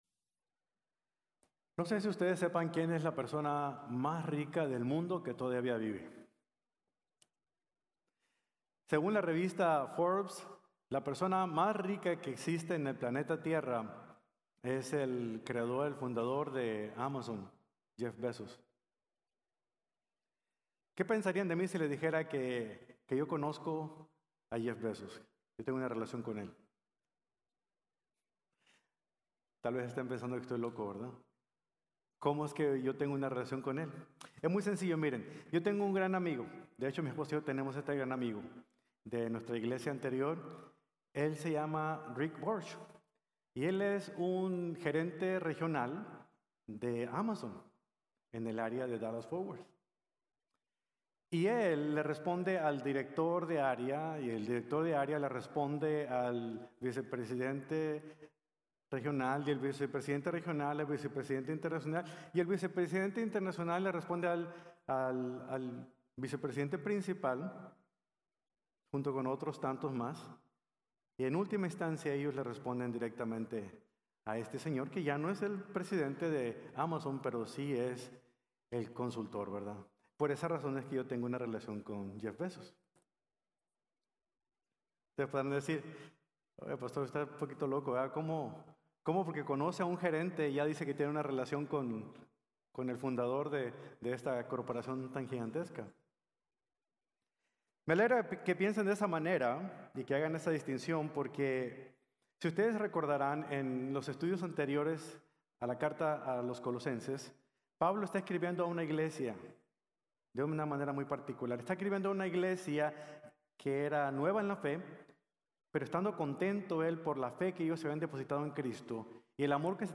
Colosenses 1:16-23 | Sermón | Iglesia Bíblica de la Gracia